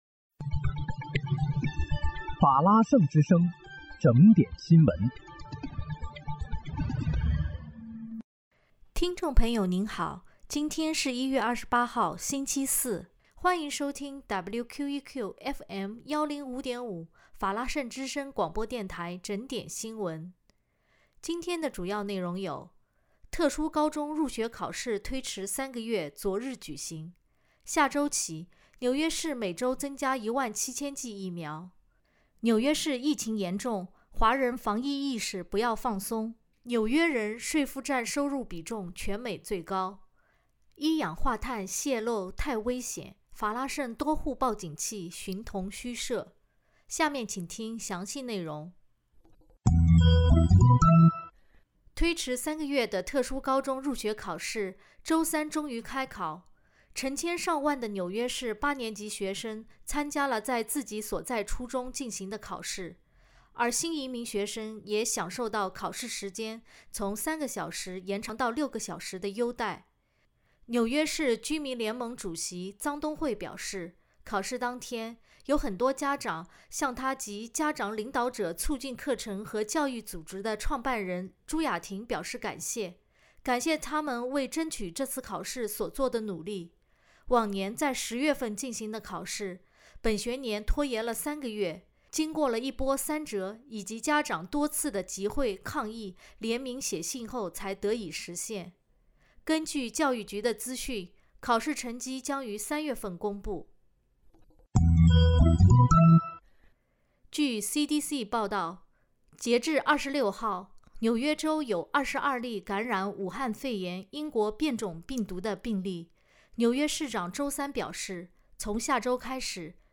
1月28日（星期四）纽约整点新闻